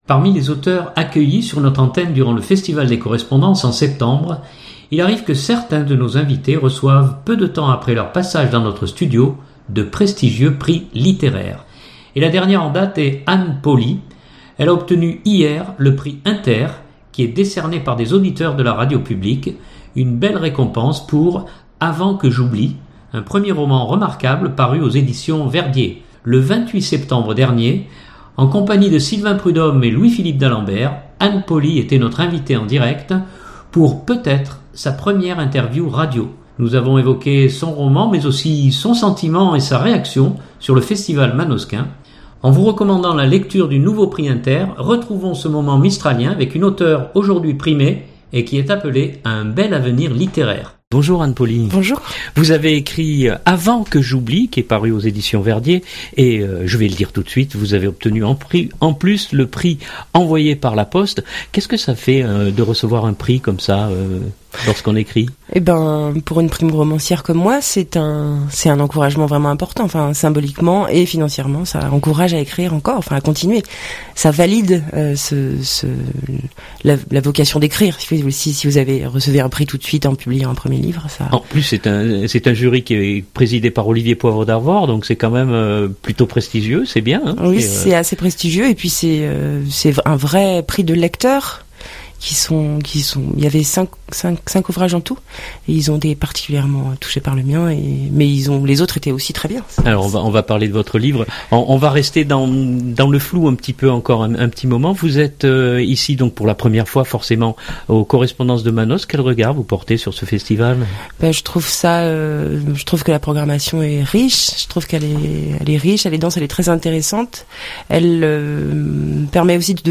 Itw
Le 28 septembre dernier, durant Les Correspondances de Manosque